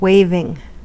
Ääntäminen
Ääntäminen US Haettu sana löytyi näillä lähdekielillä: englanti Waving on sanan wave partisiipin preesens.